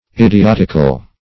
Search Result for " idiotical" : The Collaborative International Dictionary of English v.0.48: Idiotic \Id`i*ot"ic\, Idiotical \Id`i*ot"ic*al\, a. [L. idioticus ignorant, Gr.
idiotical.mp3